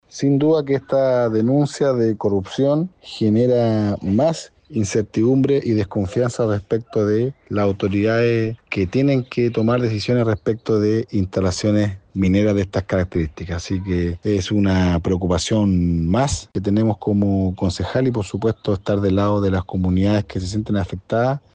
Asimismo, Renzo Aranda, concejal y militante de la Democracia Cristiana, indicó que este proyecto genera más incertidumbre y desconfianza respecto de las autoridades que tienen que tomar decisiones en torno a instalaciones mineras de estas características.